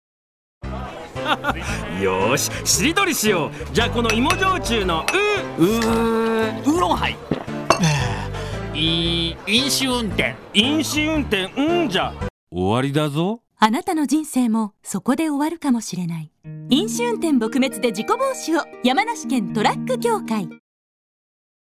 ラジオコマーシャル